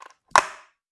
Skateboard Normal Pop.wav